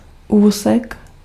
Ääntäminen
France (Normandie): IPA: /paʁ.ti/